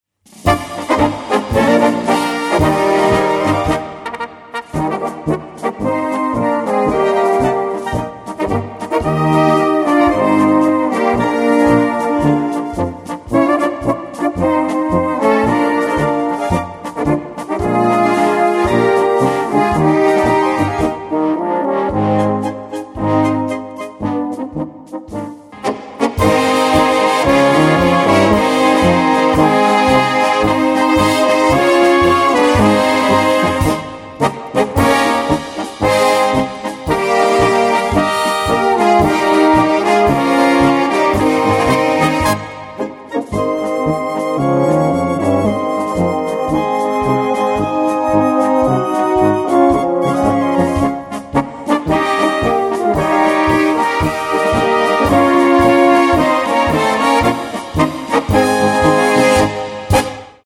Gattung: Polka
Besetzung: Blasorchester
der diese böhmische Polka musikalisch in Szene gesetzt hat!